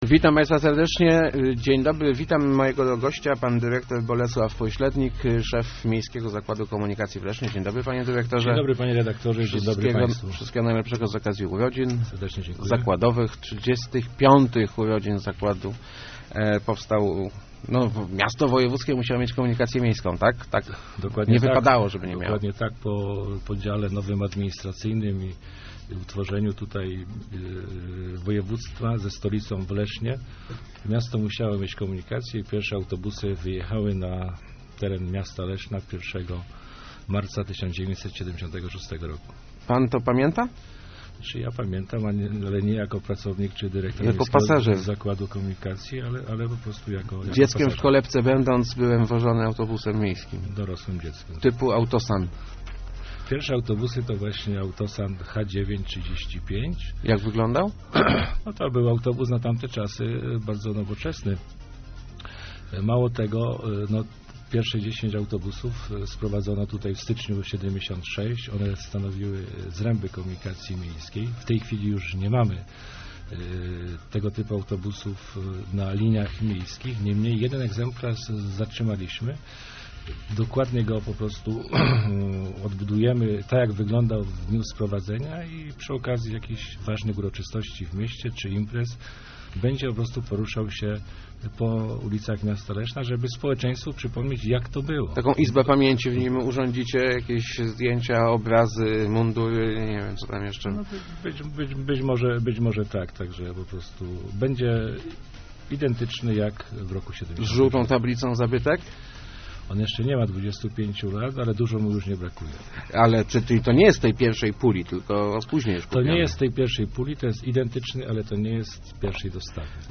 Ponad połowa naszych pasażerów jeździ za darmo - mówił w Rozmowach Elki